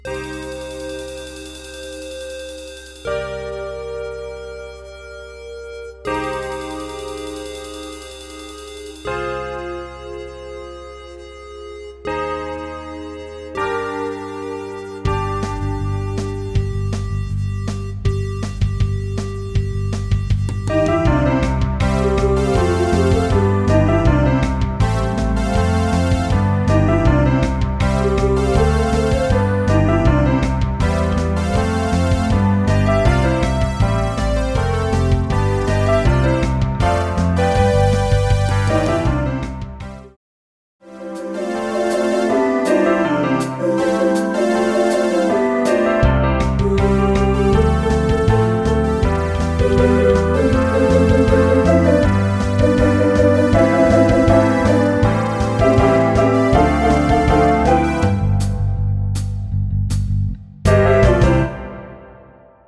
Keyboard 1 (zwei Systeme), Keyboard 2 (ein System), Keyboard 3 (zwei Systeme – Direktionsstimme), Keyboard 4 (FX Sounds/ Geräusche), Bass (möglichst E- und akustisch), Drumset, Solisten, Chor
Intro und Titelsong (digital erzeugt) | Mehr Informationen